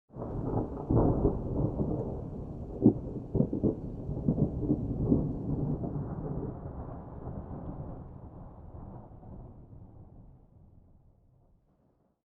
thunderfar_5.ogg